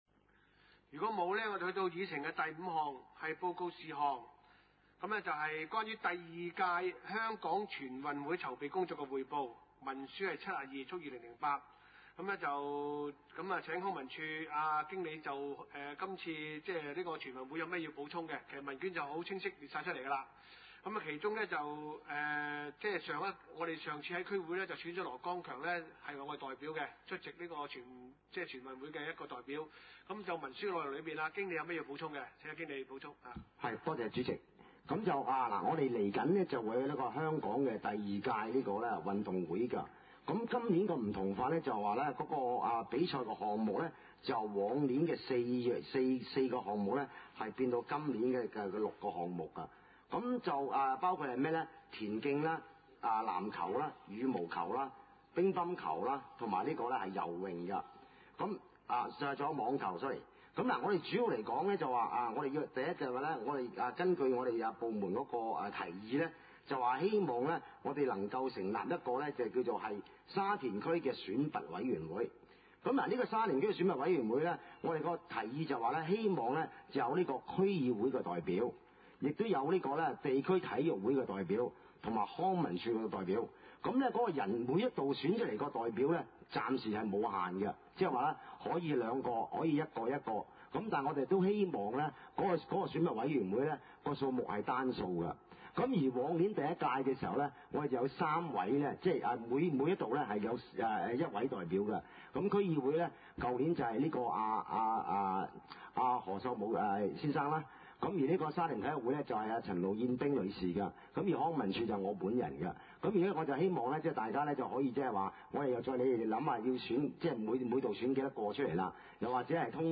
地點：沙田區議會會議室